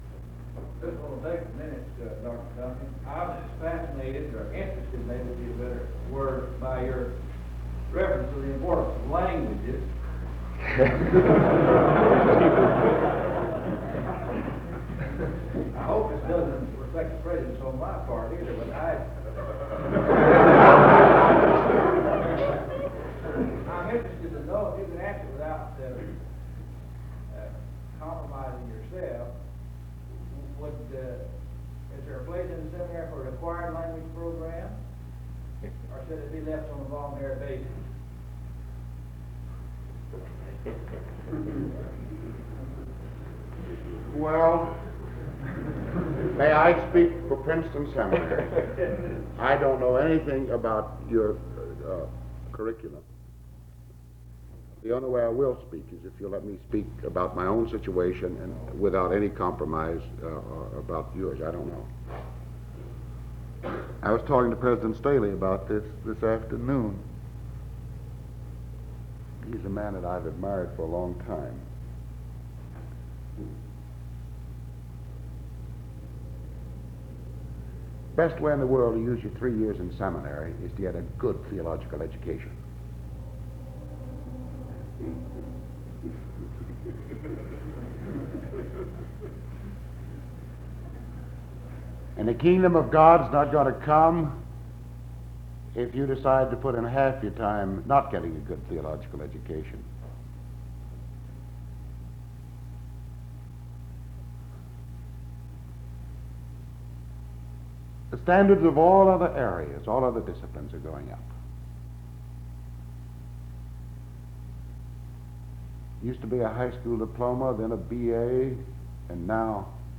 He then discusses Bultmann’s doctrine of grace and eschatology (07:43-17:51). From this, he moves on to discuss Paul Tillich’s views on existence, the Fall, soteriology, and grace (17:52-23:08). He concludes his lecture with the present advantages of studying history (23:09-29:57).